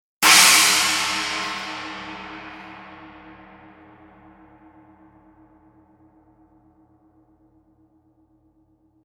破裂音のような抜けの良いアタックと独特のサスティーンでエフェクト、アクセントに最適。スティックワークによってさまざま表情を持ち独特の形状をしたカップはオーバートーンを防ぎます。